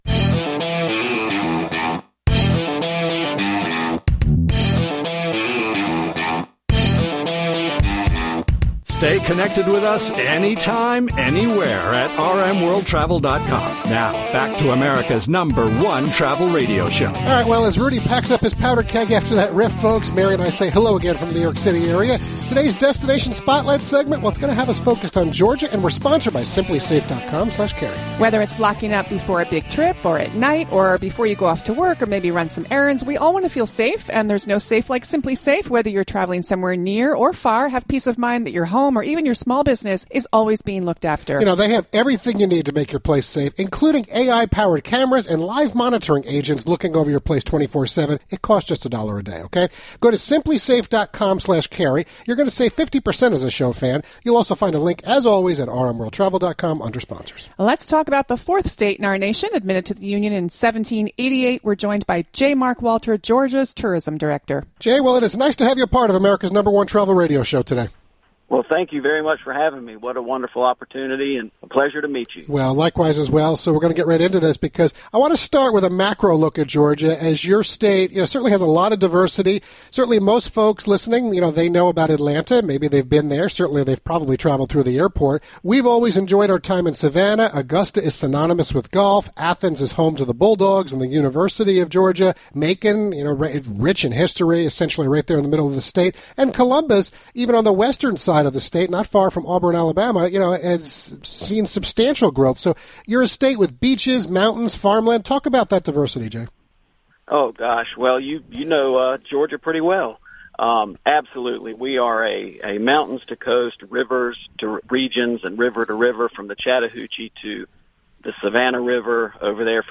During our live national broadcast of America’s #1 Travel Radio on June 21st — it was time to showcase America’s 4th State.